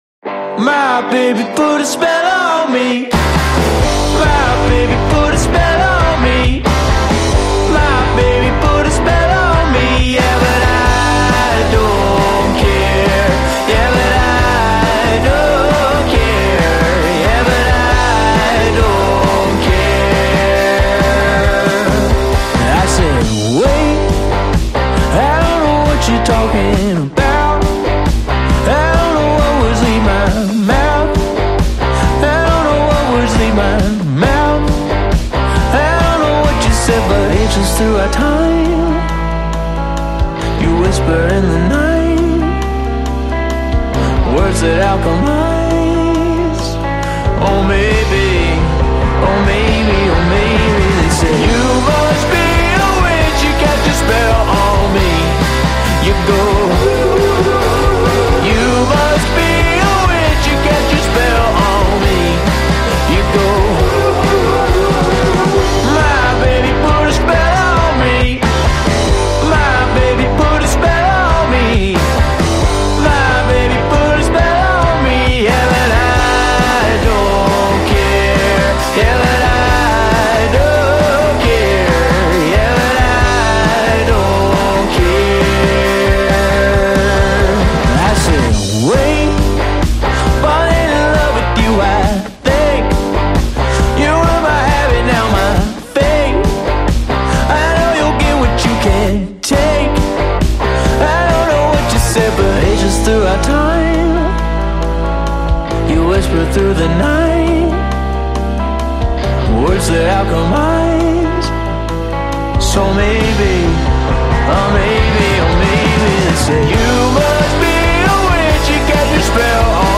indie flower rockers